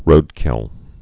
(rōdkĭl)